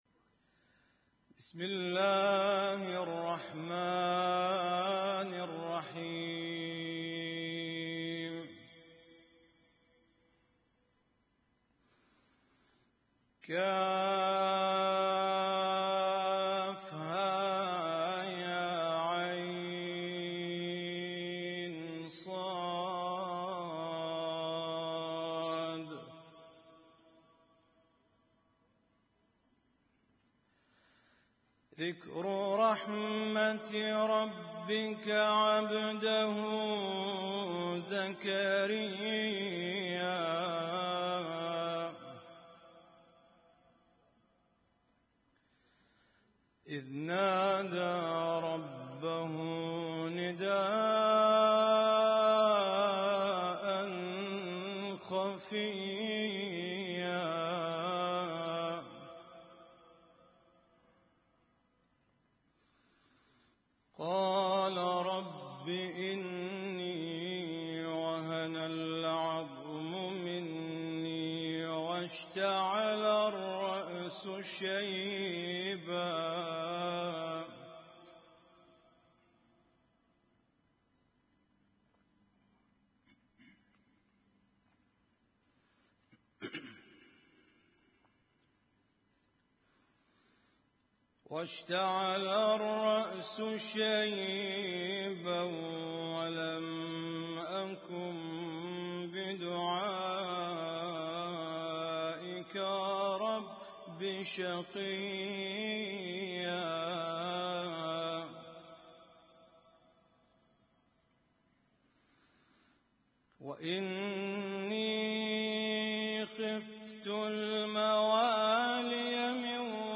Husainyt Alnoor Rumaithiya Kuwait
اسم التصنيف: المـكتبة الصــوتيه >> القرآن الكريم >> القرآن الكريم - القراءات المتنوعة